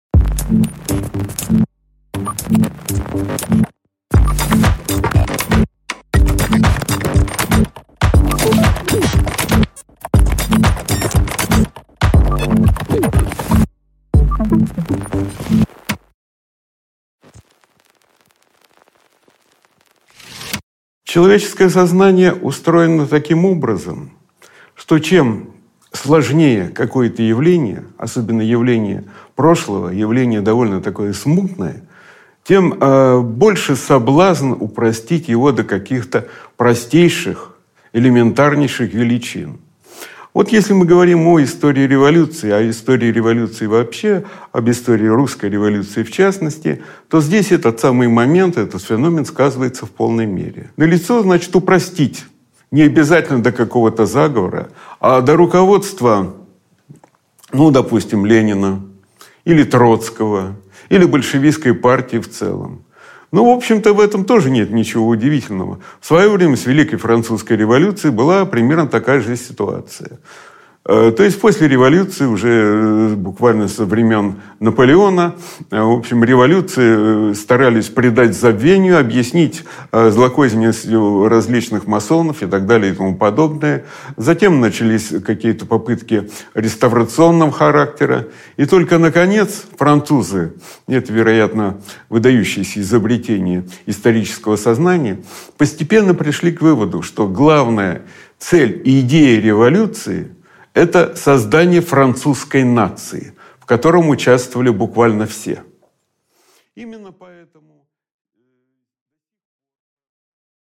Аудиокнига Механизм катастрофы | Библиотека аудиокниг
Прослушать и бесплатно скачать фрагмент аудиокниги